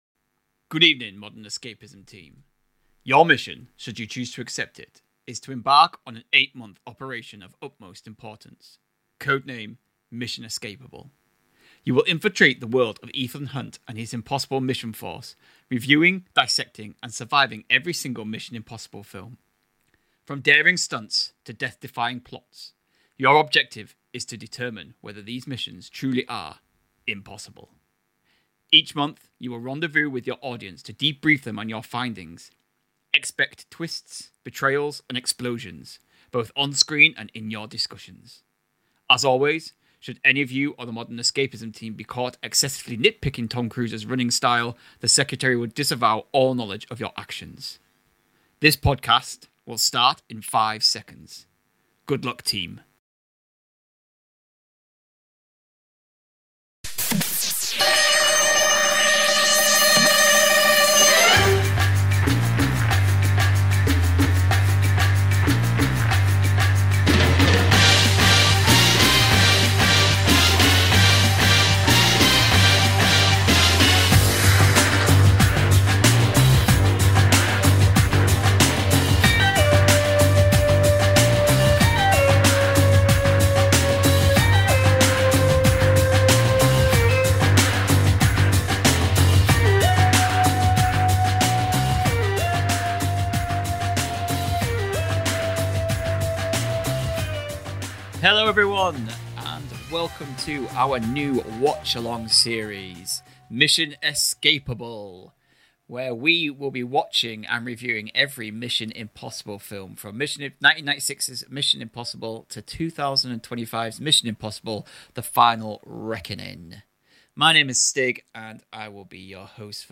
there was some electrical feedback and it wasn't discovered until the edit